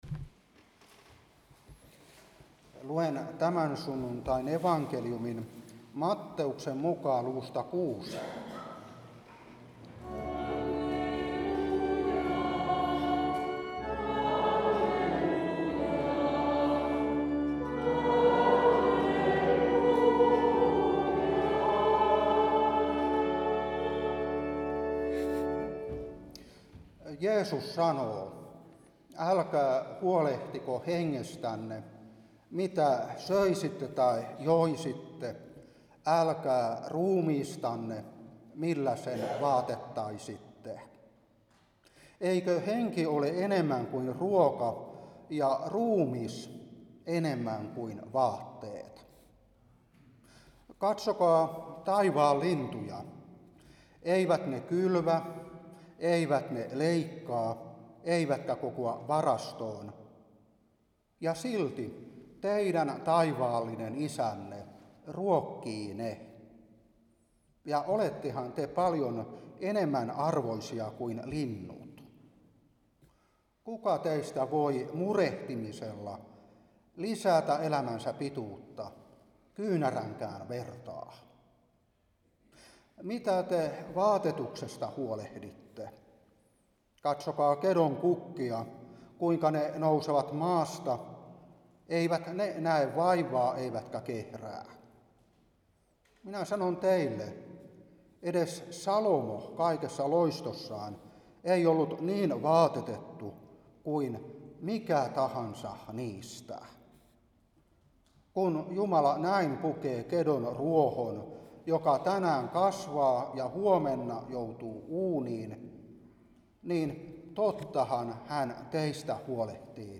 Saarna 2025-9.